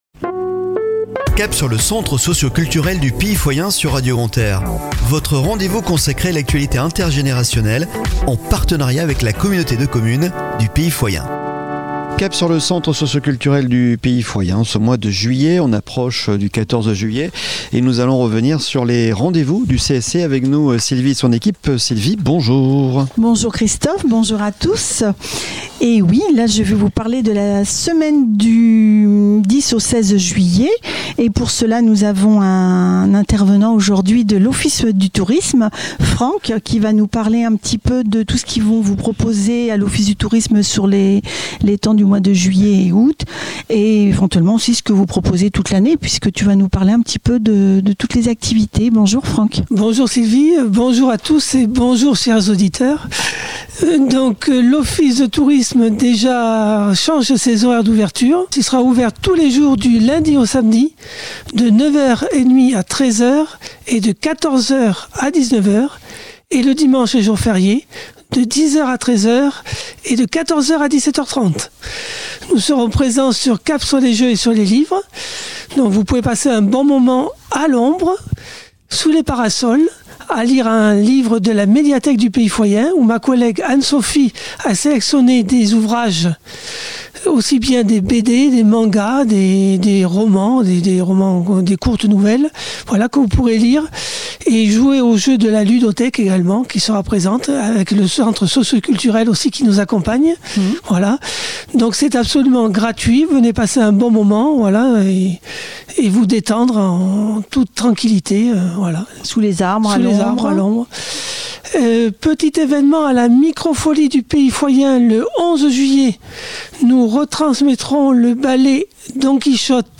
Chronique de la semaine du 10 au 16 Juillet 2023 !